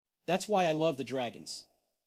→ /ðæts waɪ aɪ lʌv ðə ˈdræɡənz!/